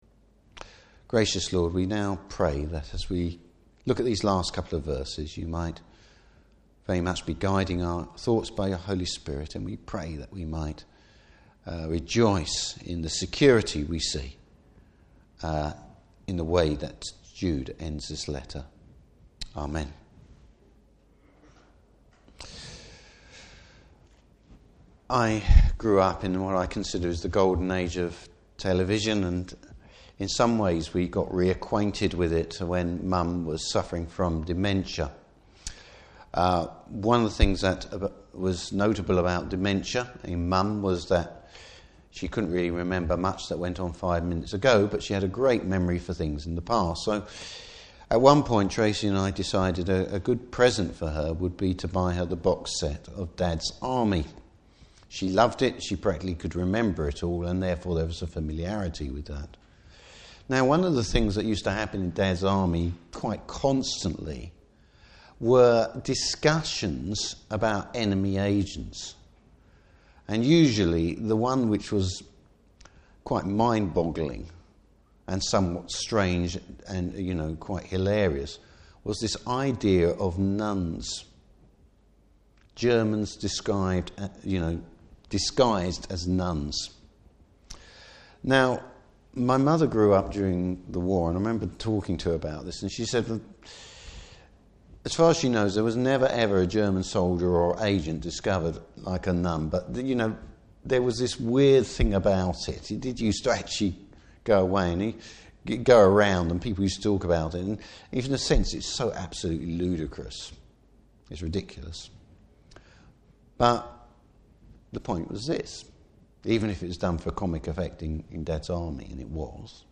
Service Type: Morning Service The security of the believer.